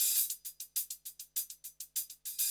HIHAT LOP4.wav